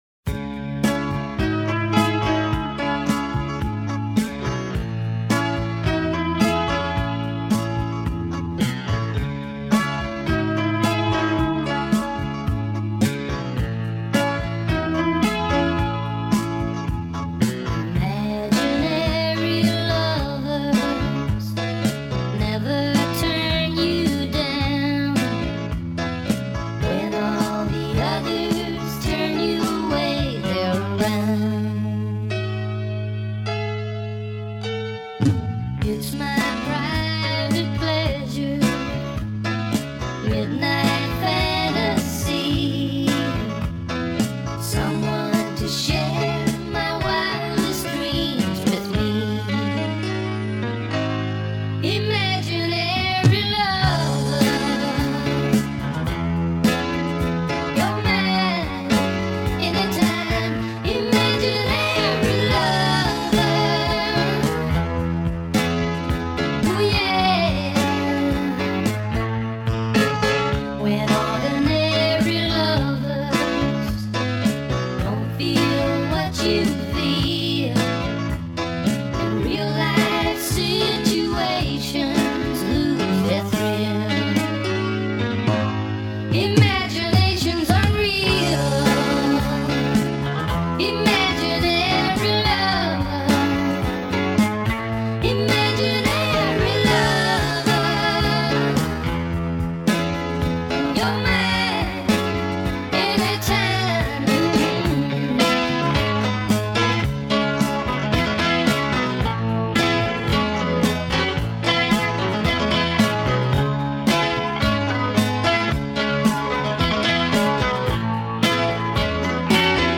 It sure does sound an awful lot like Stevie Nicks on vocals.